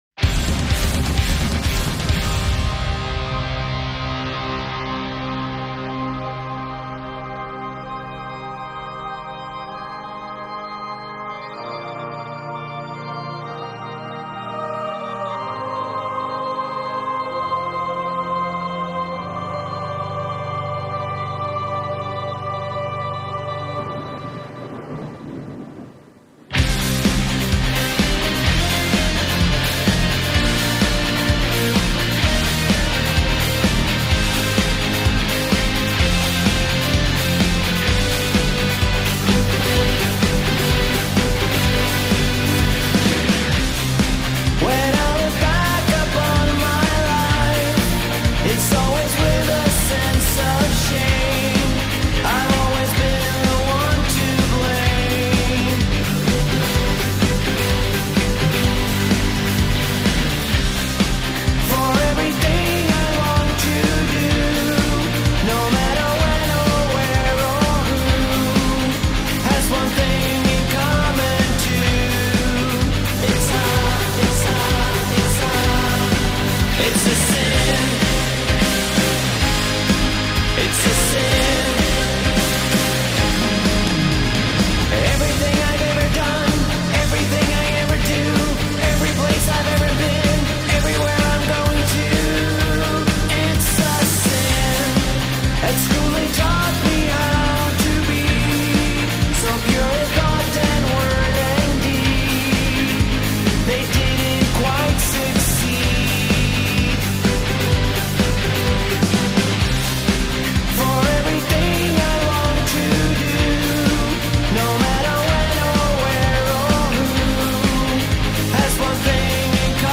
thrives in theatrical darkness
sounded so polished